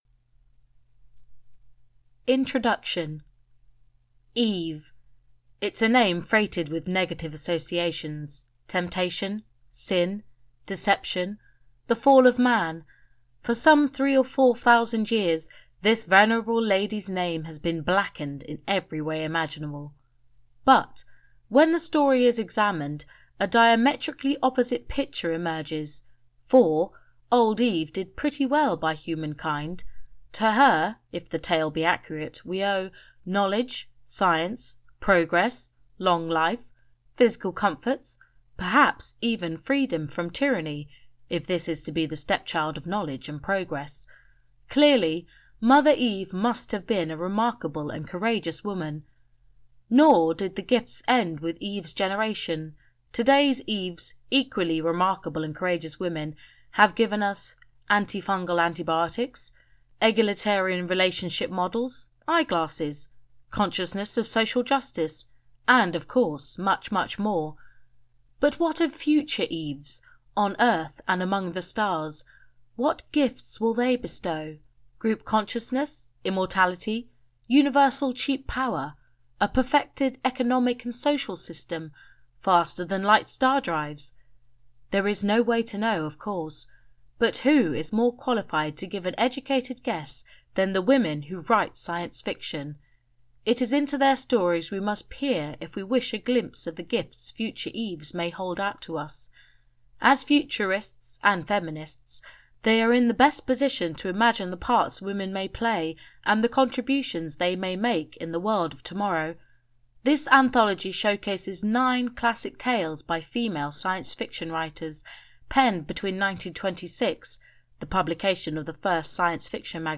THIS IS AN AUDIOBOOK